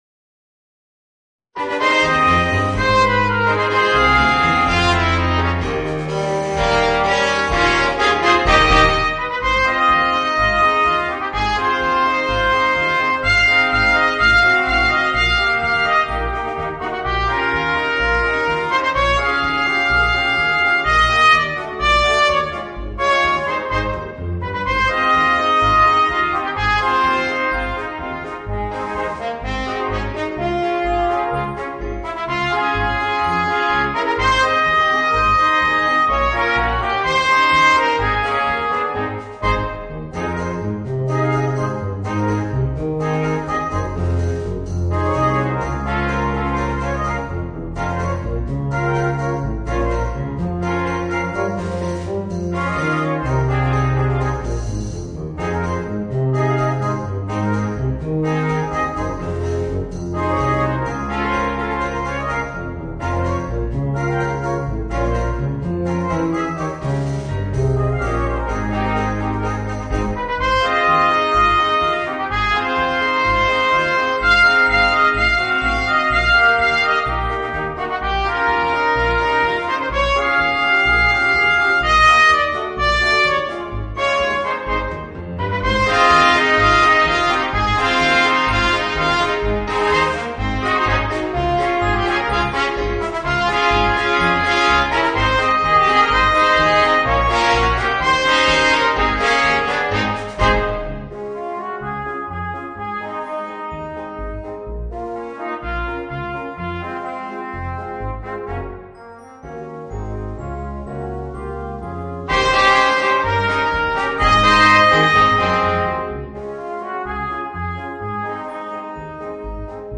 Voicing: 4 - Part Ensemble